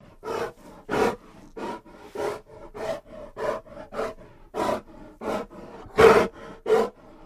Kodiak Bear Breaths Only